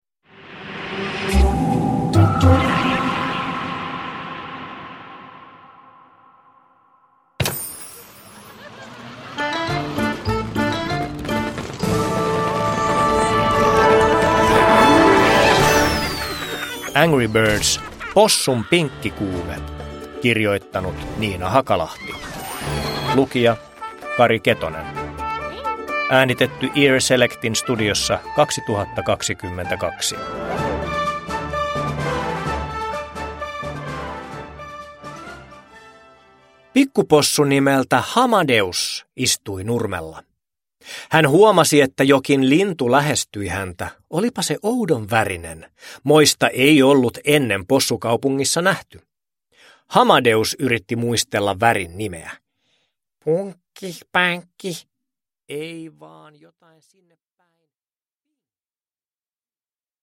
Uppläsare: Kari Ketonen